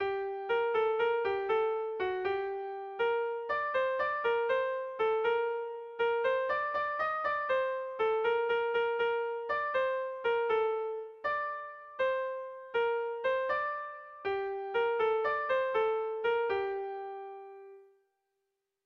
Erromantzea
ABDE